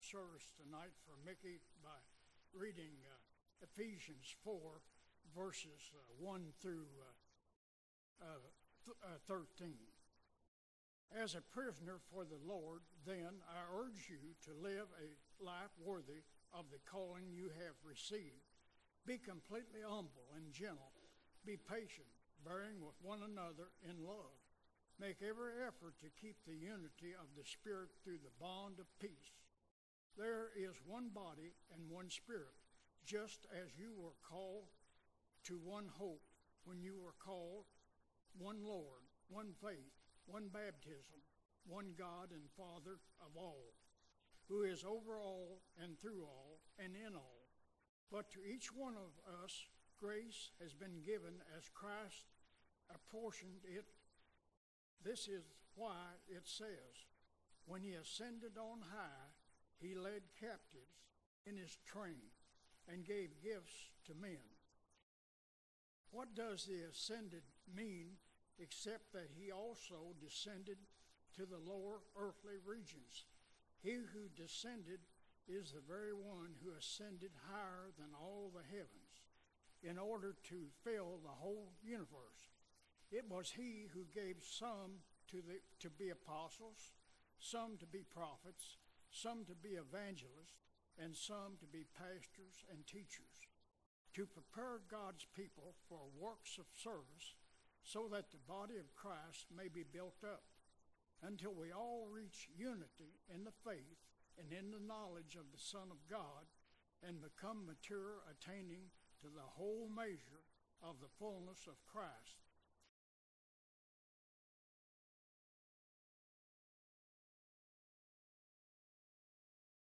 December 21, 2014 – Deacon Installation Service | Vine Street Baptist Church
The entire service was recorded and is presented below.